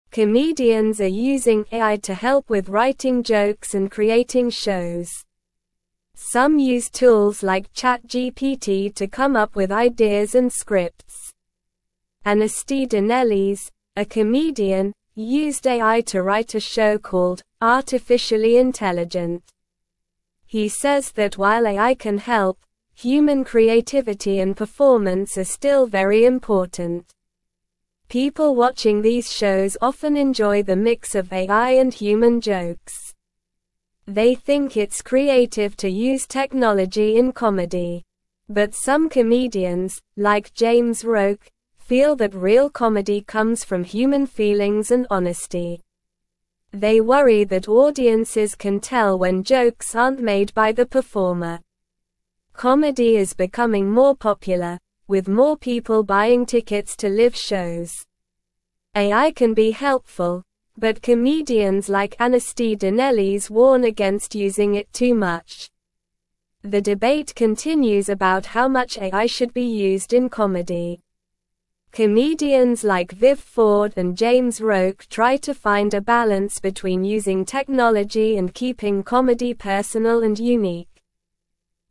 Slow
English-Newsroom-Lower-Intermediate-SLOW-Reading-Comedians-Use-Computers-to-Make-Jokes-and-Shows.mp3